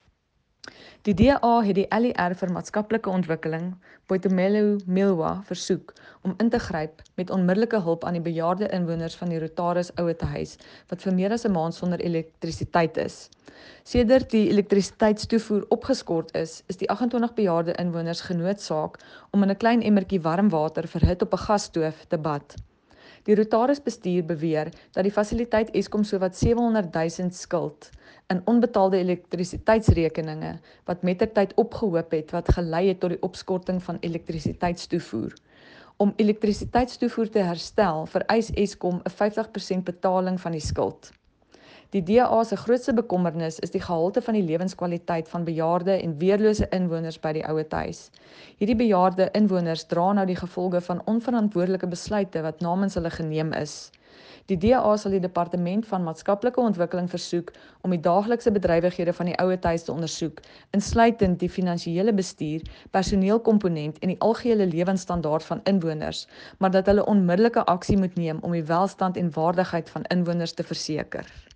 Note to Broadcasters: Please find linked soundbites in
Afrikaans by Cllr Arista Annandale.